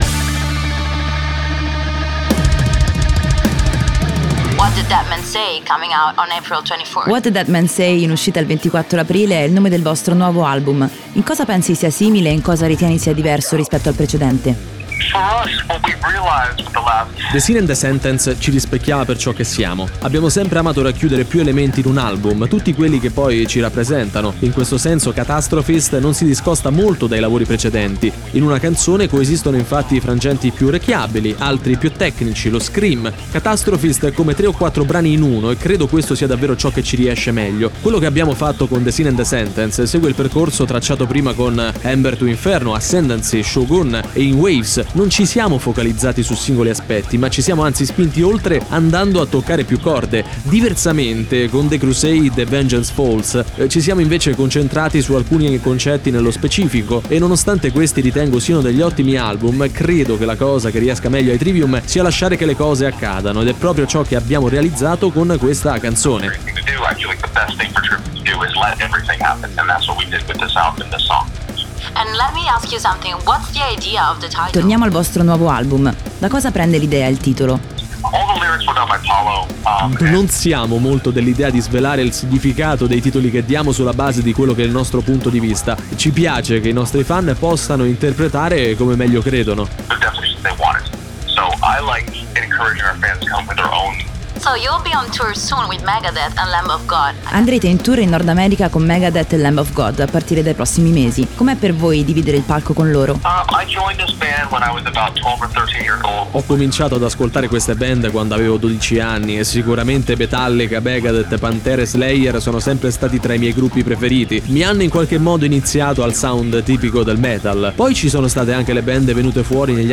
Intervista: Trivium (Matt Heafy) - "What The Dead Men Say" (24-04-20)